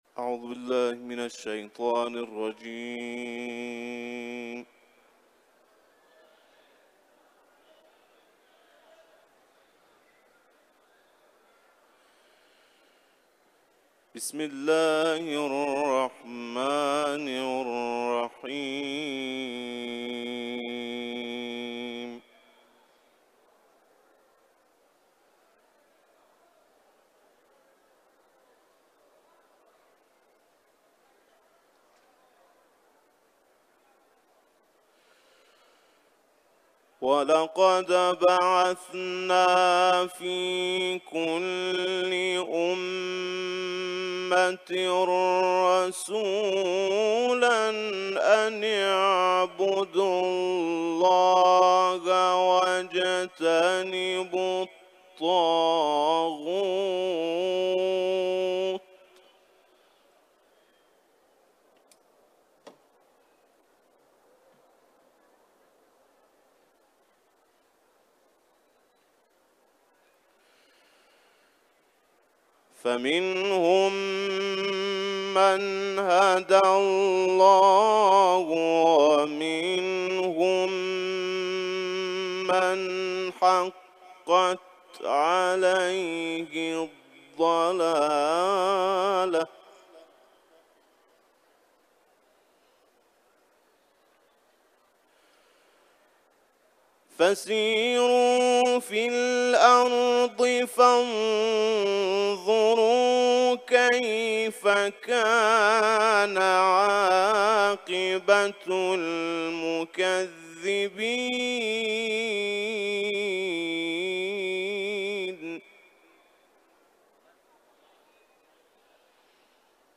Etiketler: İranlı kâri ، Kuran tilaveti ، Nahl suresi